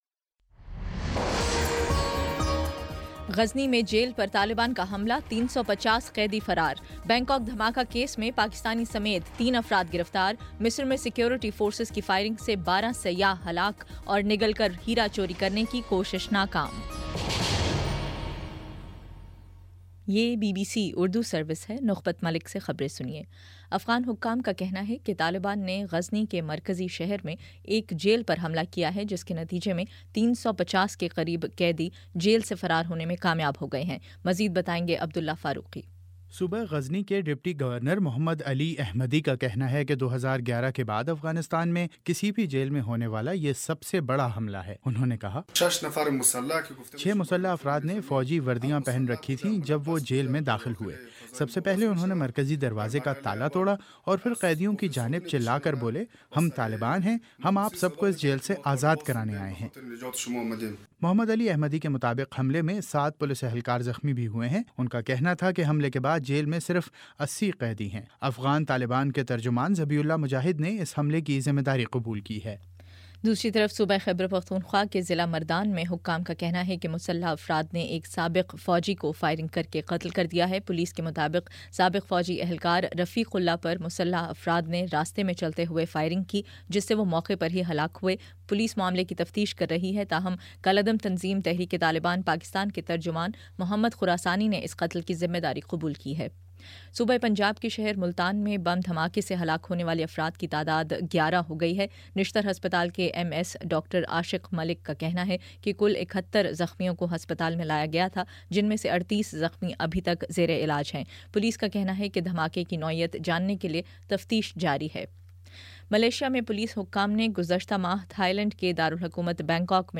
ستمبر14 : شام پانچ بجے کا نیوز بُلیٹن